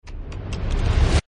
SoundEffect